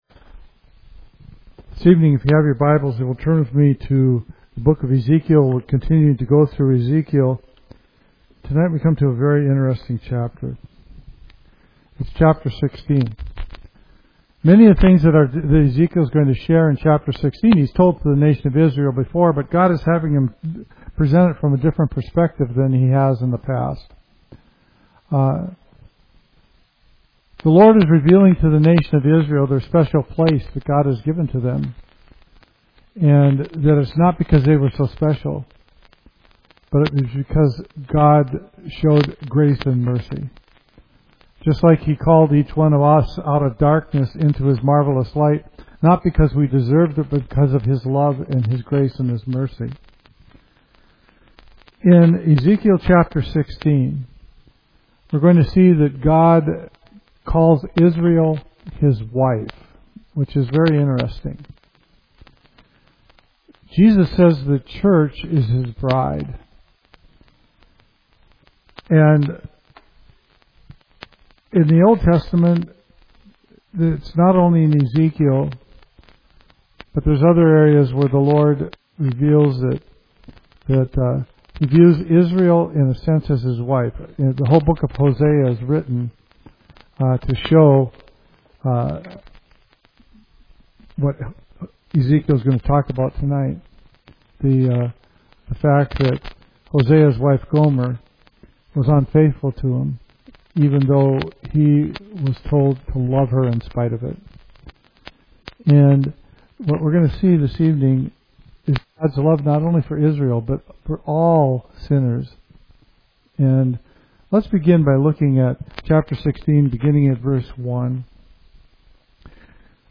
Study in Ezekiel